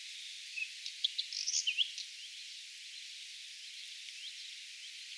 täydellisesti matkia harmaasirkkua.
jonkinlainen_harmaasirkkumatkintasae_harmaasirkkupaikan_pensastaskulta.mp3